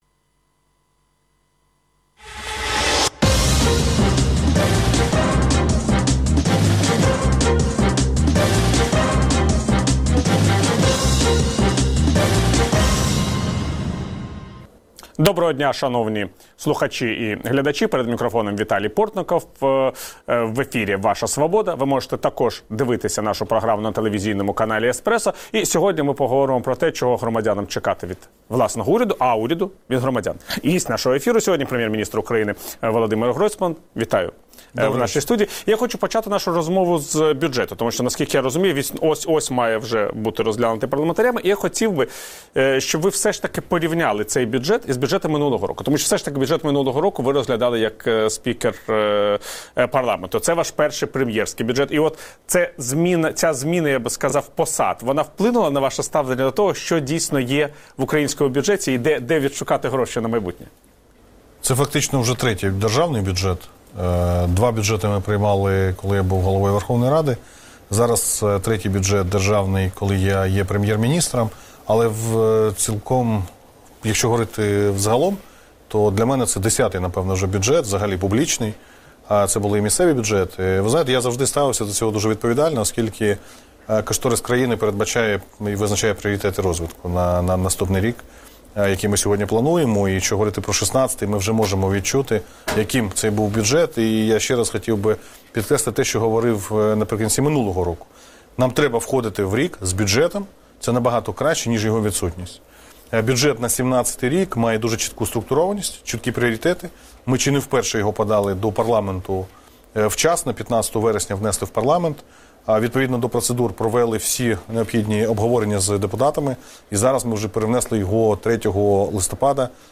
Ваша Свобода | Інтерв’ю прем’єр-міністра України Володимира Гройсмана